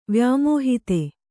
♪ vyāmōhite